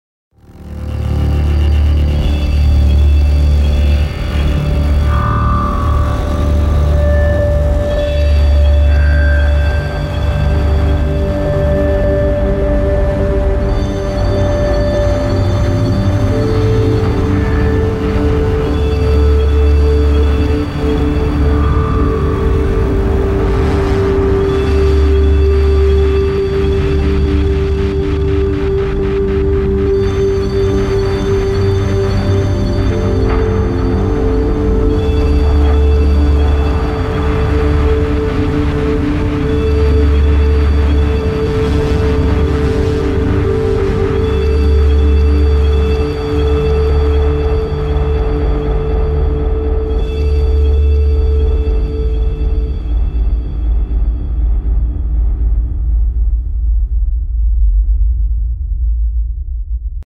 field_desertisland.mp3